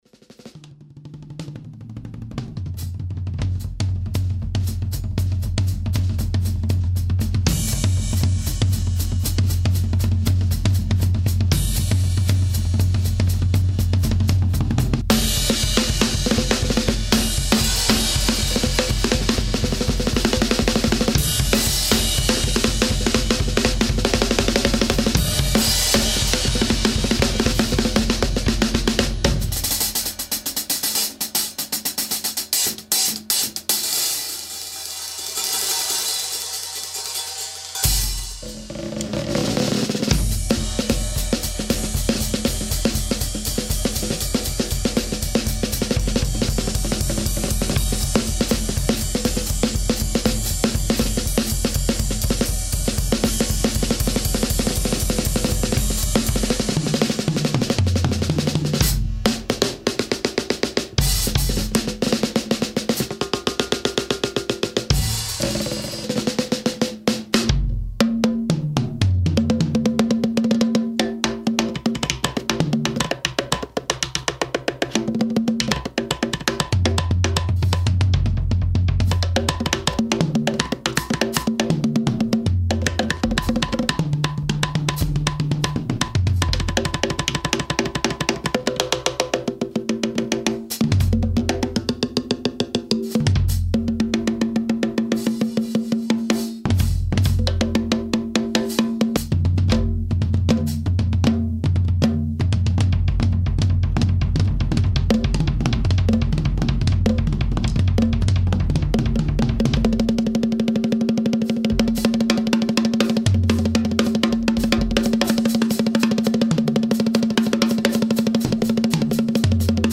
Instrumento . Bateria -Percusión
Calentando manos  antes de una      grabación
muestra de bateria.mp3